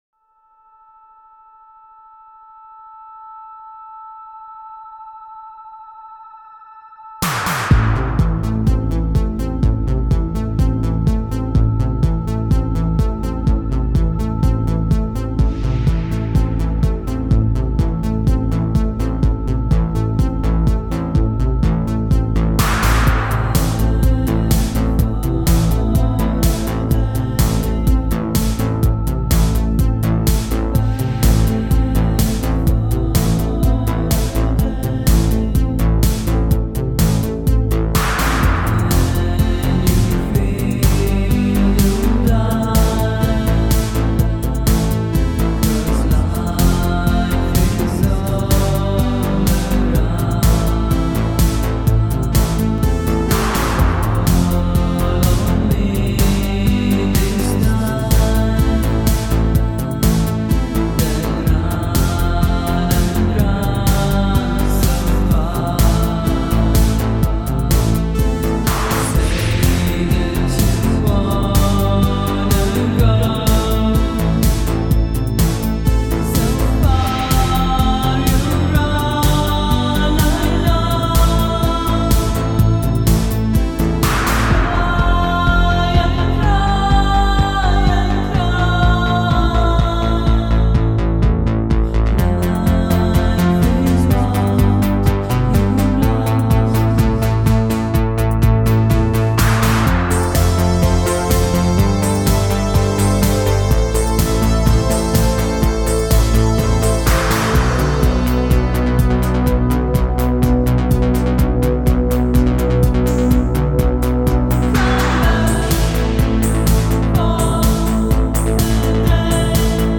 like a decent throwback to the eighties